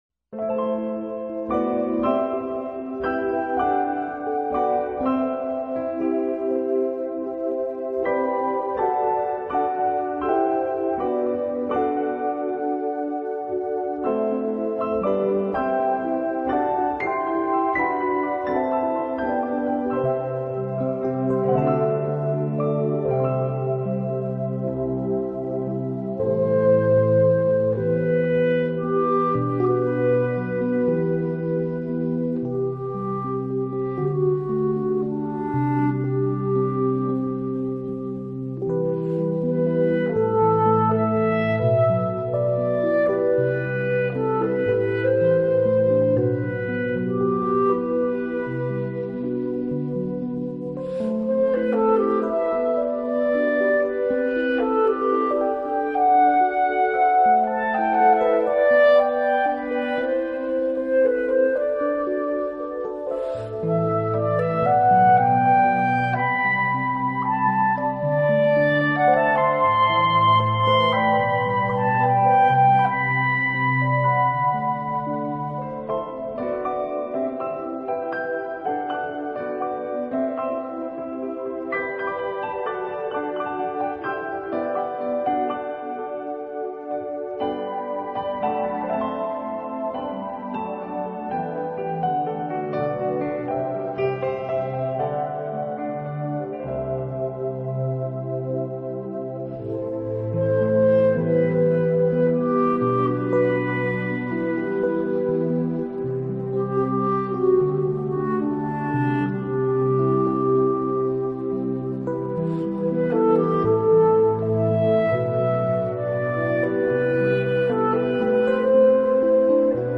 New Age音乐专辑
纯净的和声，糅进了，添染了凯尔特的元素，虽然平缓的，没有过多的起伏，却如同我们看见的水波
而低音管和单簧管的缓缓的吟唱，大提琴的舒缓，小提琴和长笛的攀延翱翔，却在这里产生